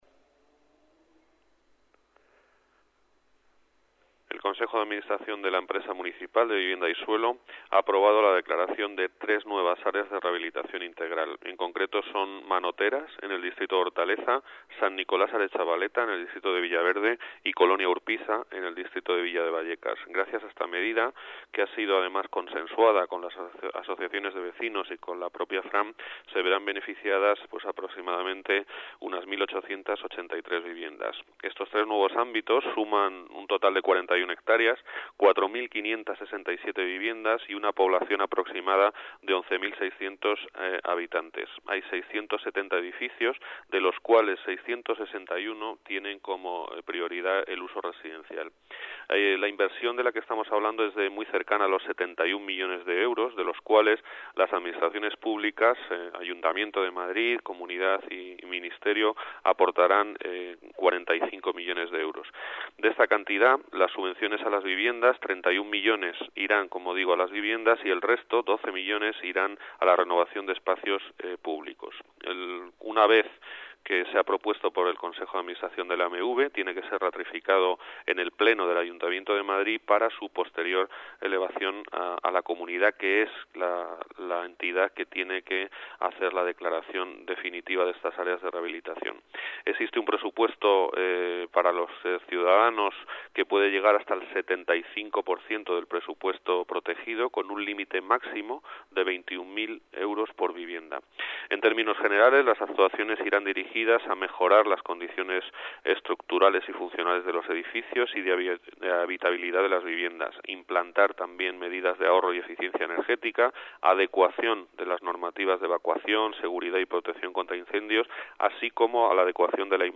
Nueva ventana:Declaraciones de Juan José de Gracia, coordinador general de Vivienda